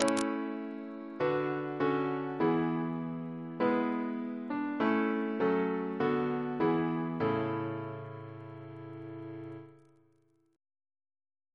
CCP: Chant sampler
Single chant in A Composer: Edwin George Monk (1819-1900), Organist of York Minster Reference psalters: ACB: 32; RSCM: 195